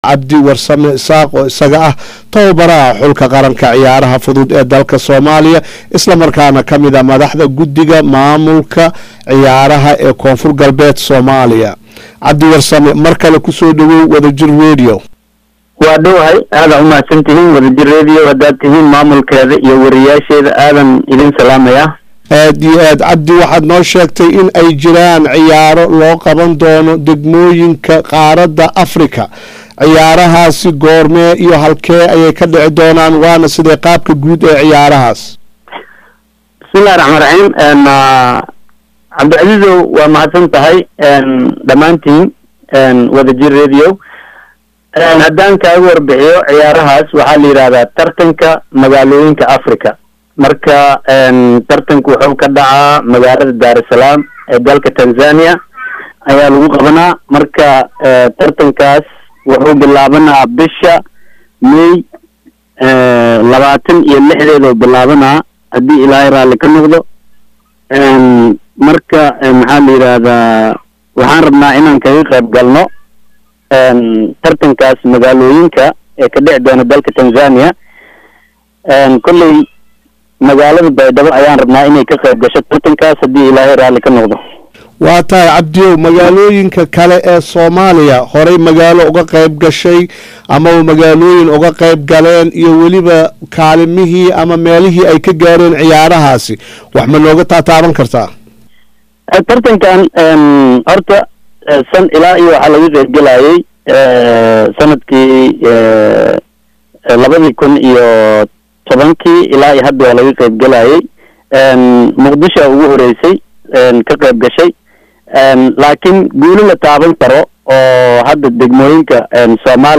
Hadaba si aan wax uga ogaano tartanka iyo ka qaybgalka magaalada Baydhaba ay ka qaybgalayso ayaa waxaan ka wareysanay